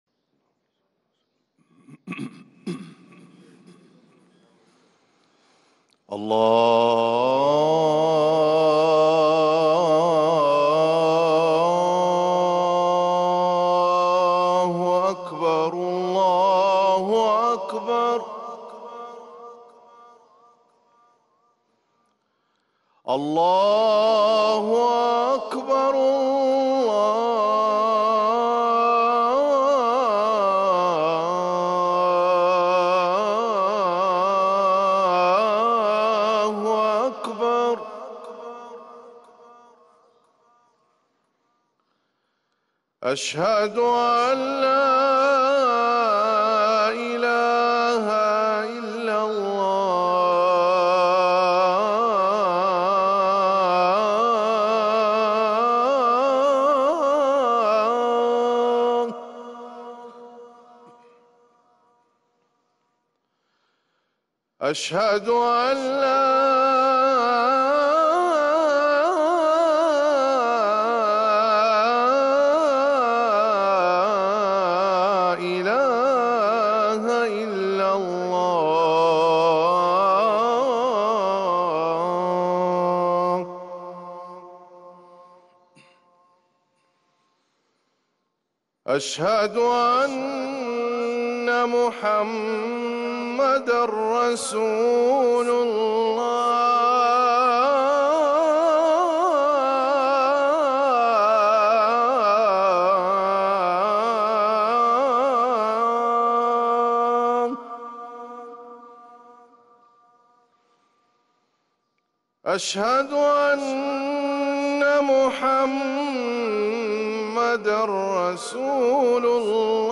> روائع الأذان > ركن الأذان 🕌 > المزيد - تلاوات الحرمين